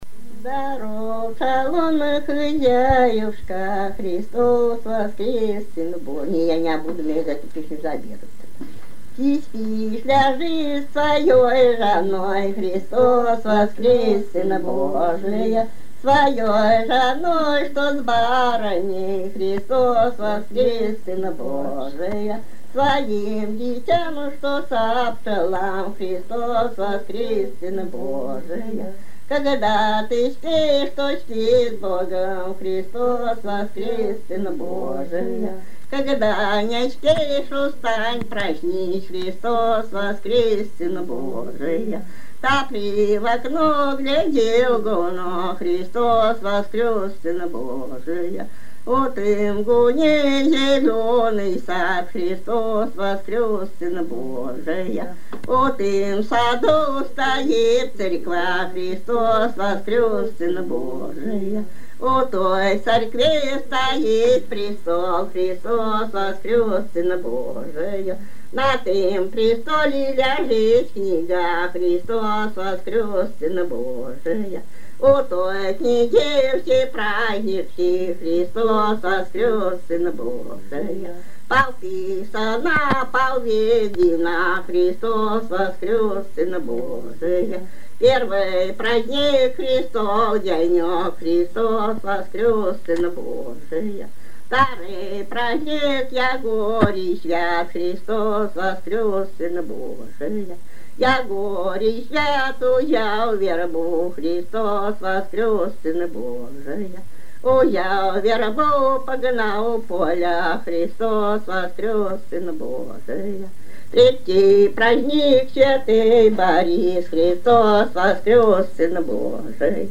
Лалымная песня.